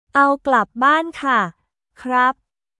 アオ・グラップ・バーン・カ／クラップ